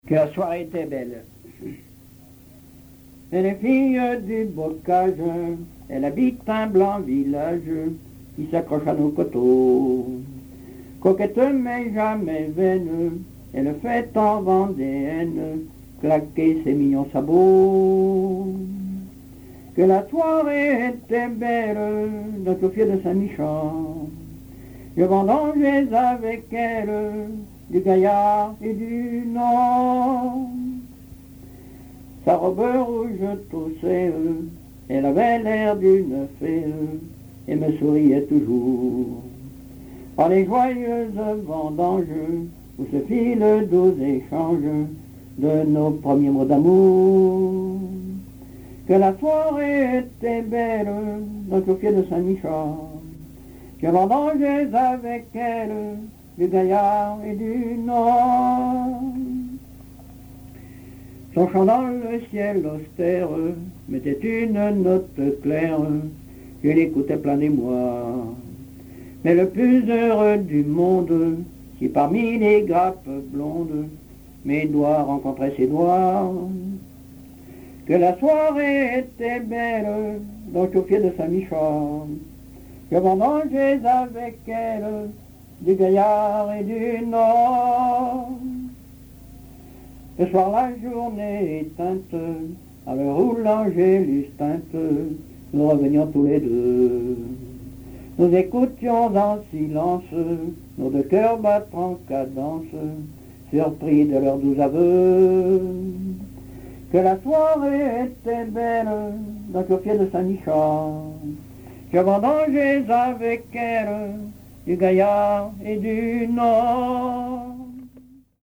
Mémoires et Patrimoines vivants - RaddO est une base de données d'archives iconographiques et sonores.
Enquête Arexcpo en Vendée-Association Joyeux Vendéens
chansons populaires
Pièce musicale inédite